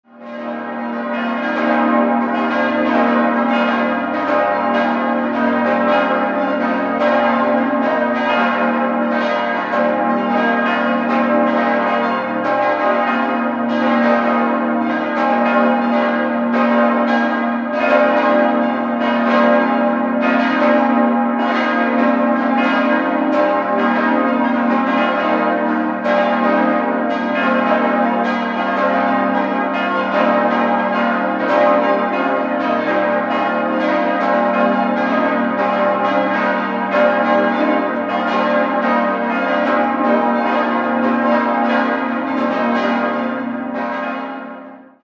Eines der größten und tontiefsten Geläute im Bistum Regensburg, dessen drei große Glocken allerdings, vom Klangbild her zu urteilen, gekröpft aufgehängt sein dürften. Die sieben neuen Glocken stehen im Vergleich zur alten Isidorglocke b' deutlich zu hoch.
Vilsbiburg_Stadtpfarrkirche.mp3